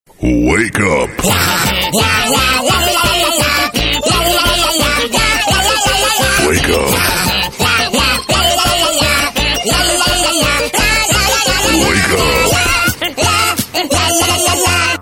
• Качество: 128, Stereo
веселые
заводные
прикольные
сумасшедшие
Веселый рингтон на будильник